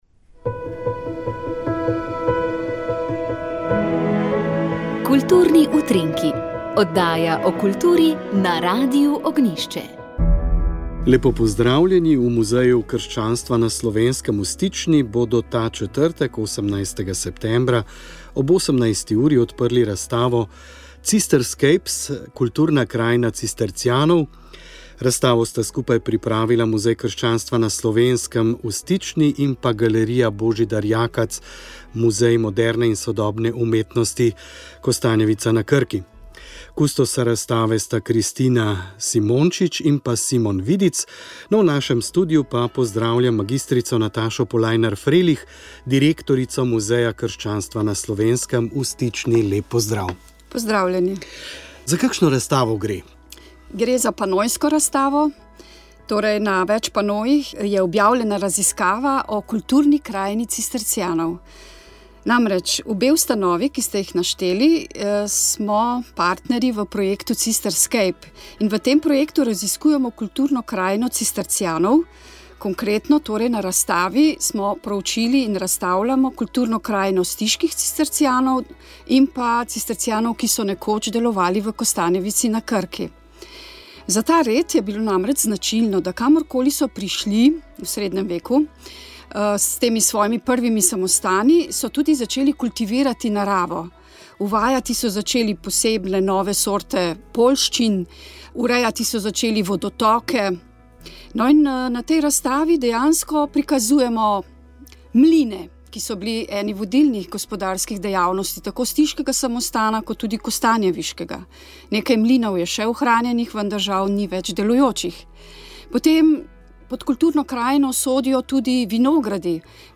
V torek 2. decembra obeležujemo Mednarodni dan boja proti suženjstvu. V tej luči smo z gosti govorili o položaju v Sloveniji, številkah s tega področja in zakonskih pomanjkljivostih ter mogočih rešitvah.